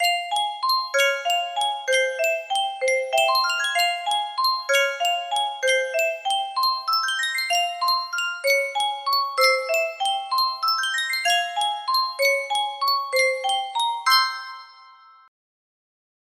Yunsheng Music Box - Franz Liszt Dance of the Gnomes 4630 music box melody
Full range 60